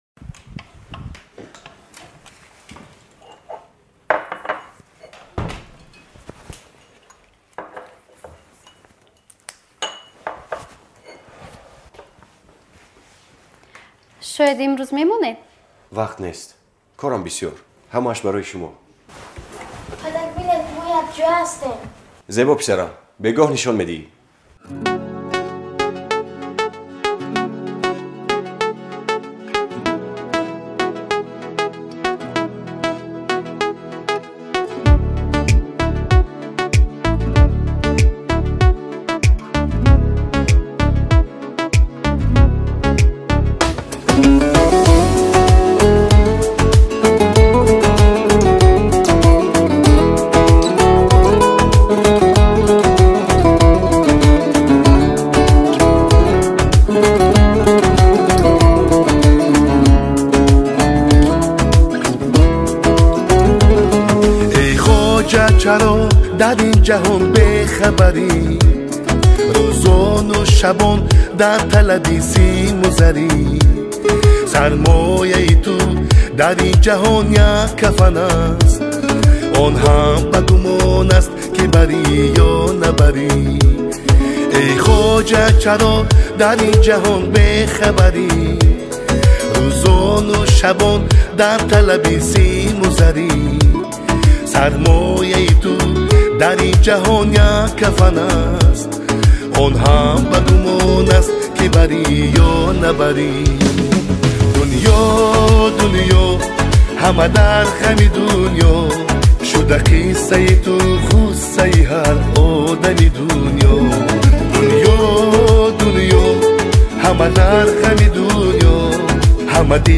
• Категория: Таджикские песни